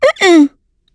Estelle-Vox-Deny_a.wav